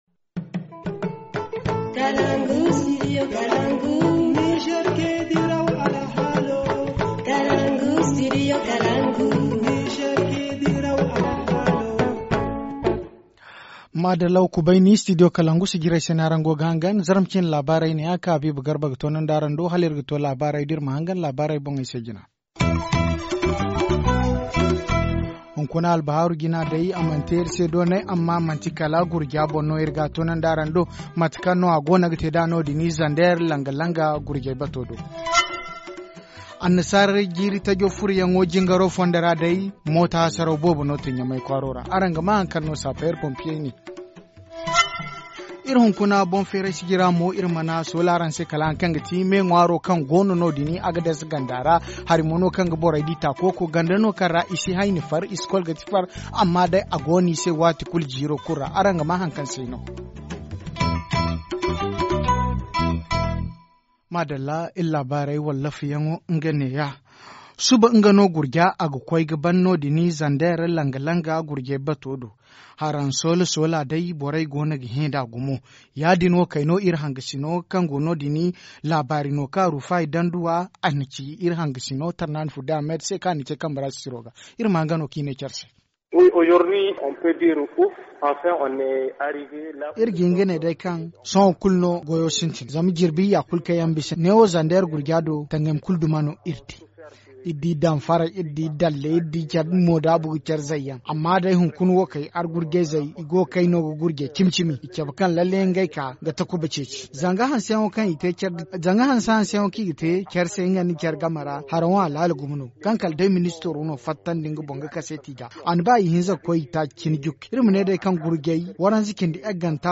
Journal en zerma